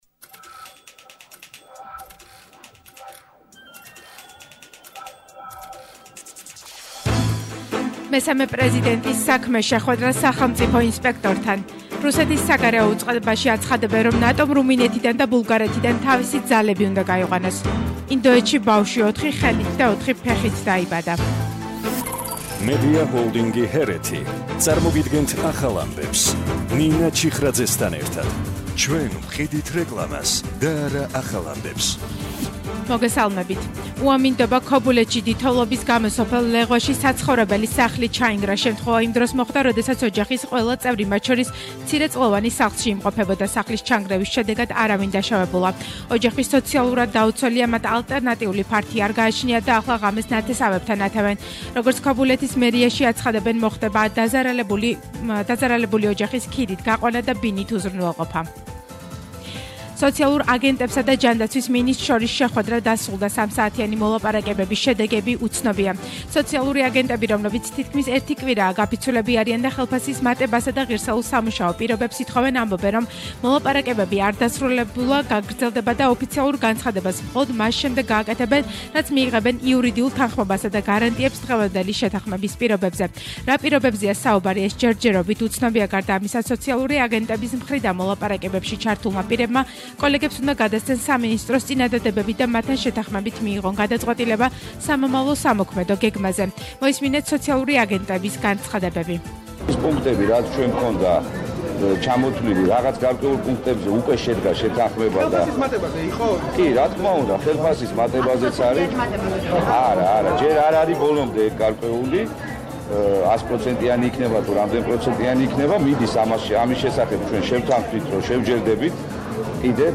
ახალი ამბები 16:00 საათზე – 21/01/22